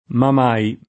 ma m#i]: Se d’Arpia battezzata ovver Giudea Ma’ mai t’hanno ghermito ugne famose [Se dd arp&a battezz#ta ovv%r Jud$a ma m#i t anno germ&to un’n’e fam1Se] (Giusti)